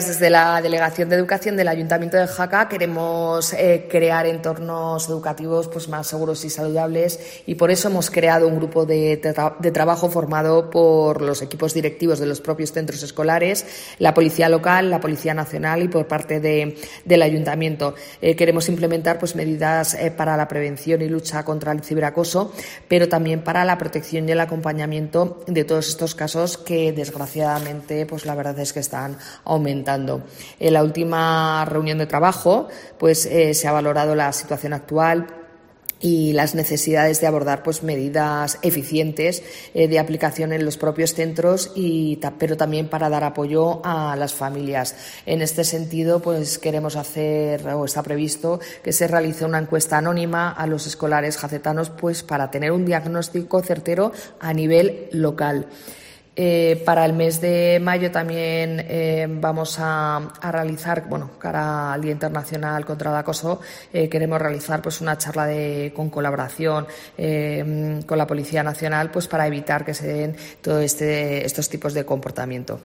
Marta Moreno, concejal de Educación en el Ayuntamiento de Jaca